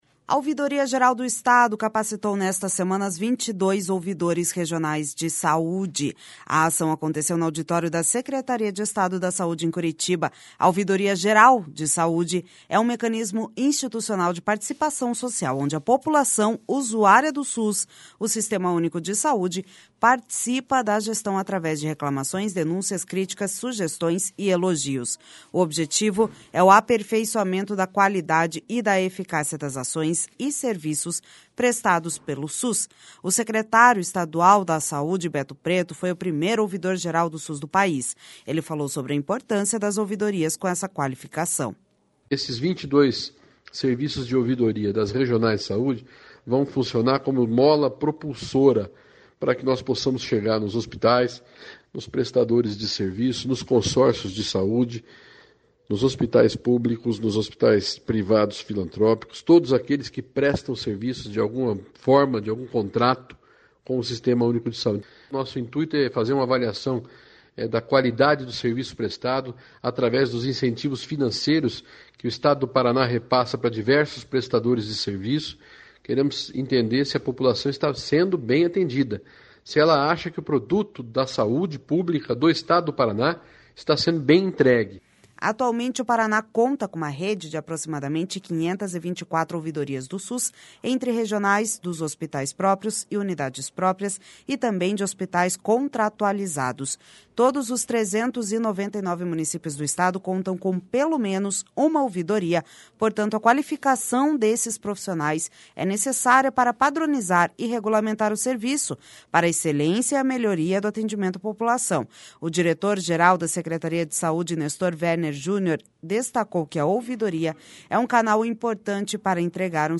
Ele falou sobre a importância das ouvidorias com essa qualificação.// SONORA BETO PRETO//Atualmente, o Paraná conta com uma rede de aproximadamente 524 ouvidorias do SUS, entre regionais, dos hospitais próprios e unidades próprias, e também de hospitais contratualizados.